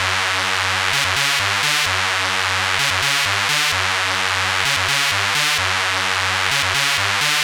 Lead 129-BPM 2-F#.wav